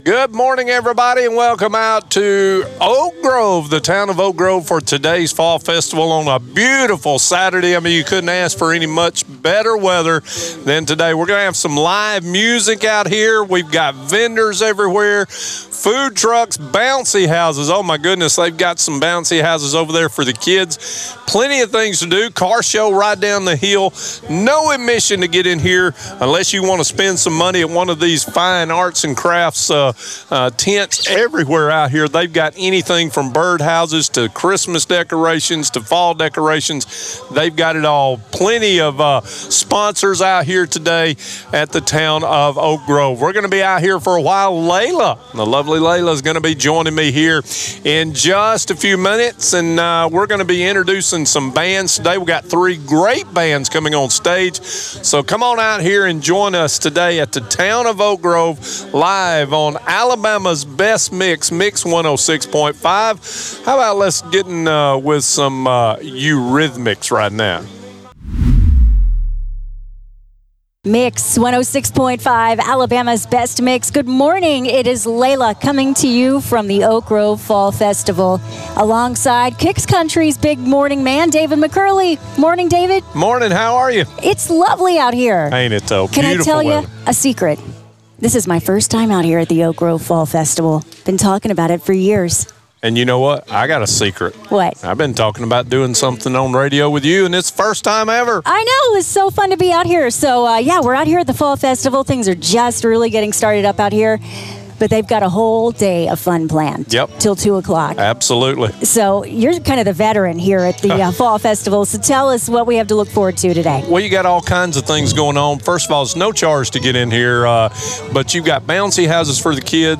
Live from 2025 Oak Grove Fall Festival